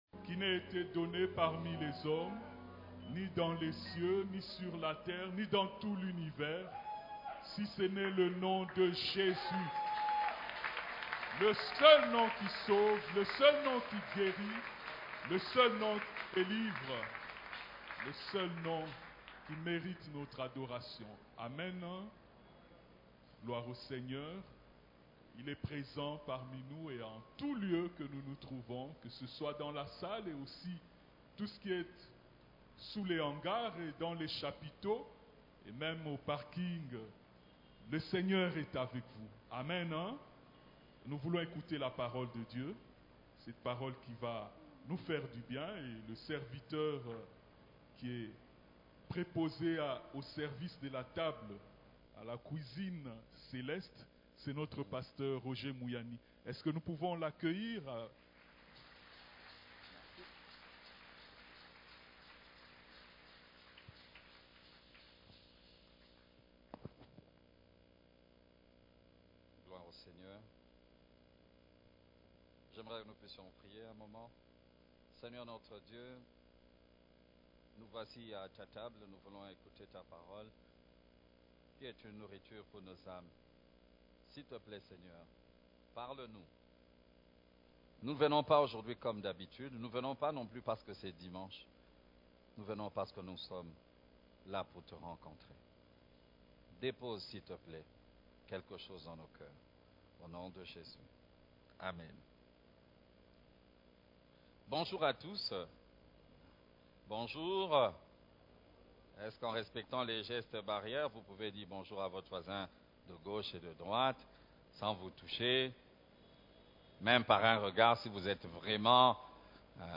Cef la Borne, Culte du dimanche de 21 février 2021 : Avance en profondeur, et jetez vos filets